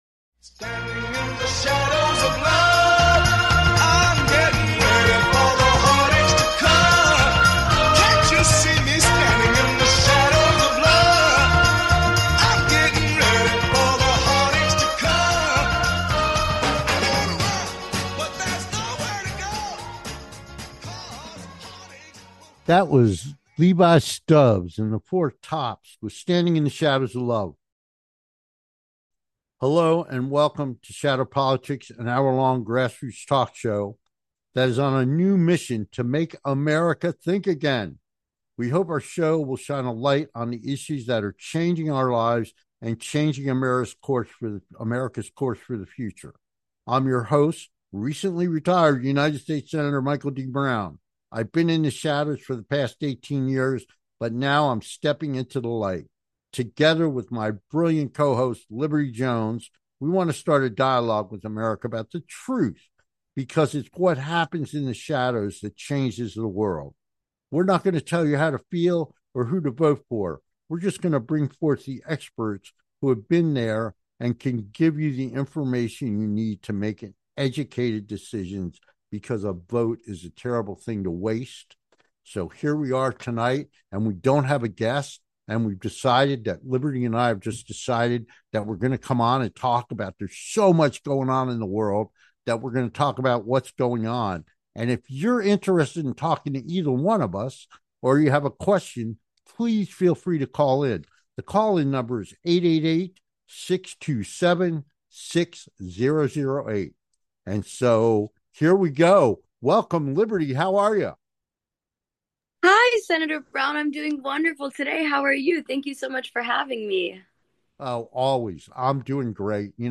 Host Senator Michael Brown
Shadow Politics is a grass roots talk show giving a voice to the voiceless.